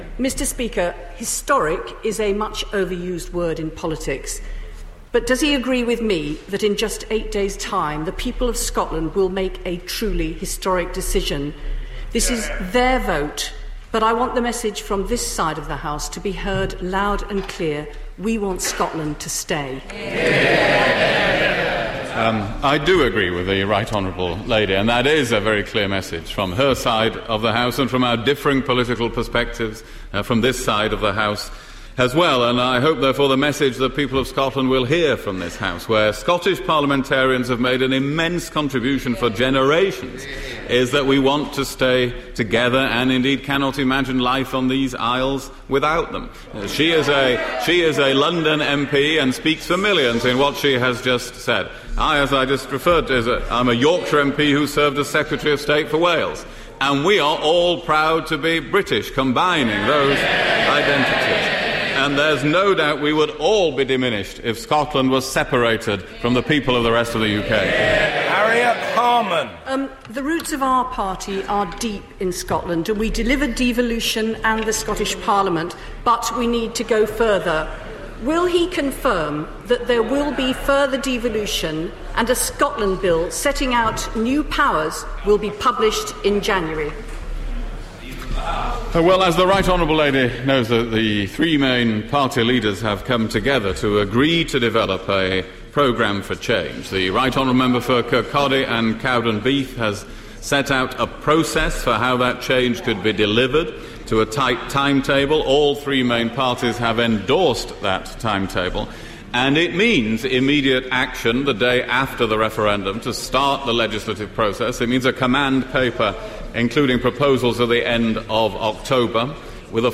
PMQs, 10 September 2014